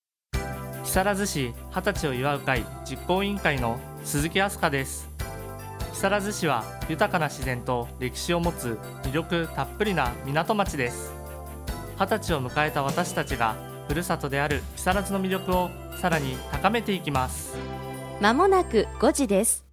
木更津の魅力をPRするCMを放送しています！
出演者：木更津市二十歳を祝う会実行委員会